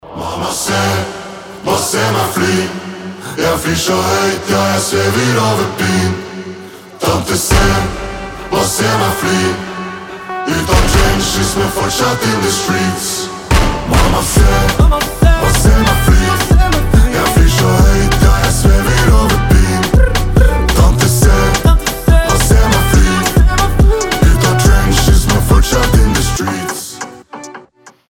• Качество: 320, Stereo
Хип-хоп